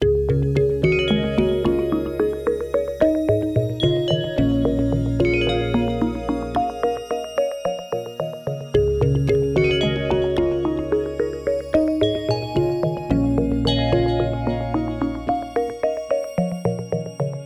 incoming-call.mp3